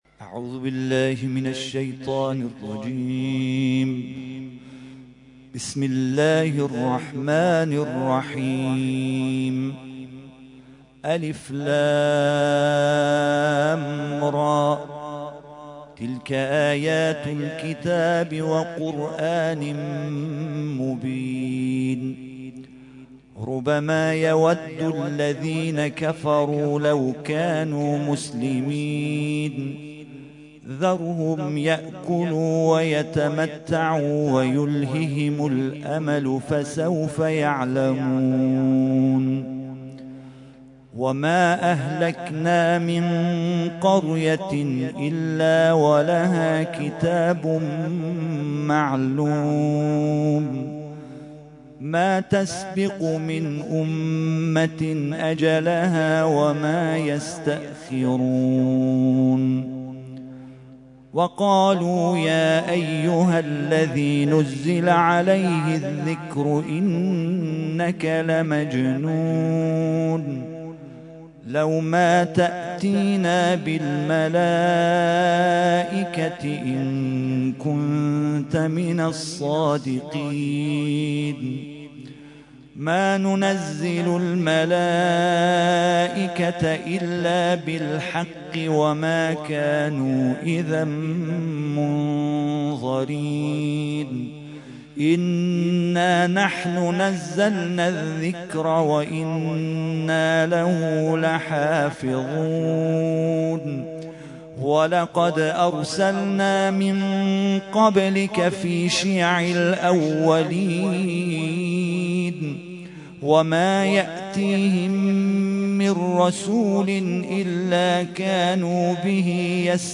ترتیل خوانی جزء ۱۴ قرآن کریم در سال ۱۳۹۸